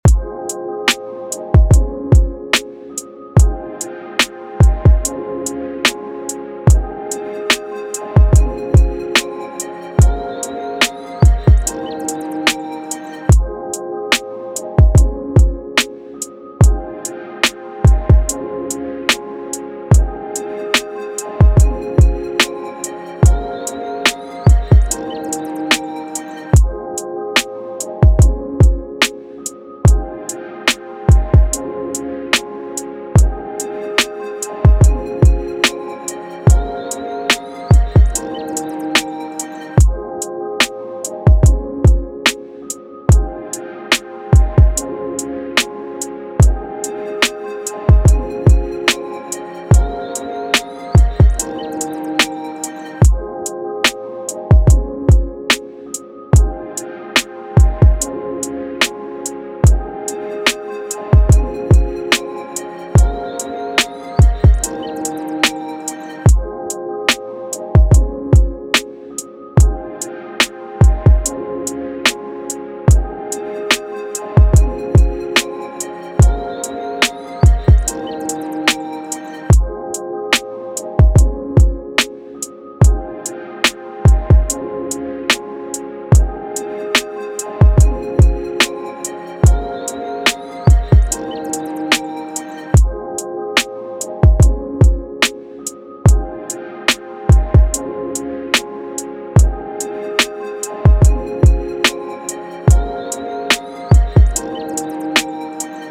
R&B
Ab Major